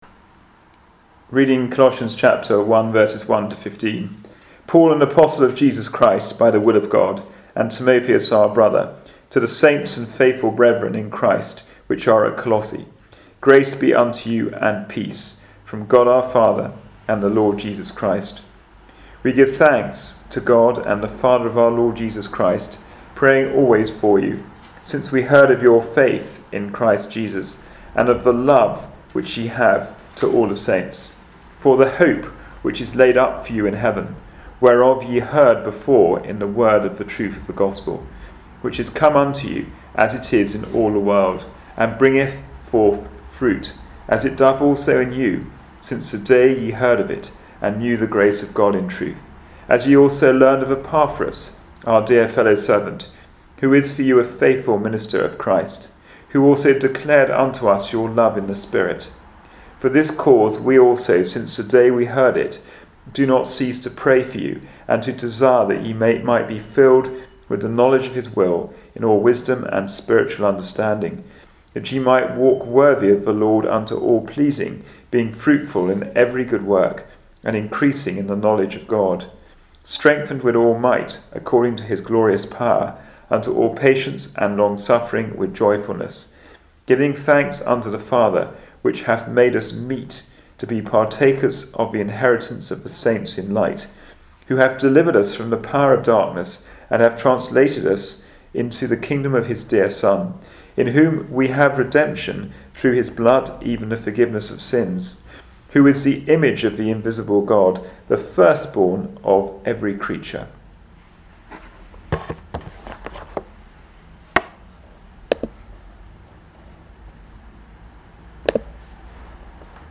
Passage: Colossians 1:15 Service Type: Wednesday Bible Study